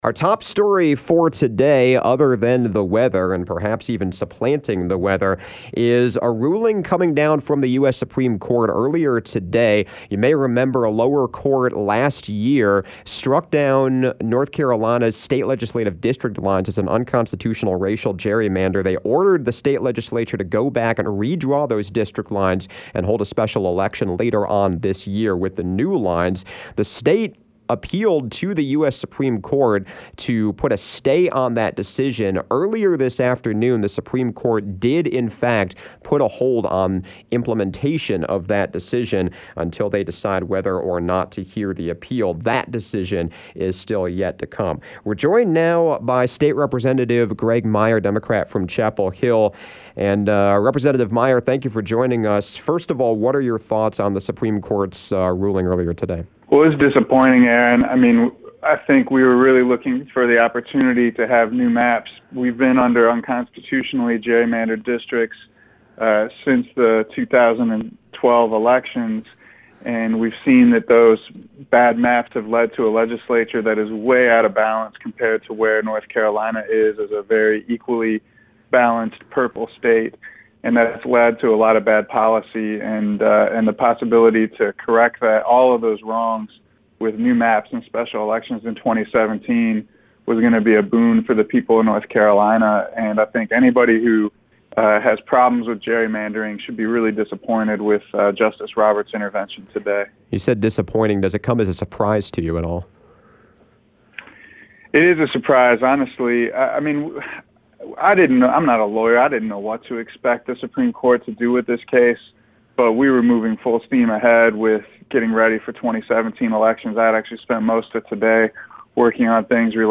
Local House Representative Graig Meyer spoke with WCHL on Tuesday afternoon.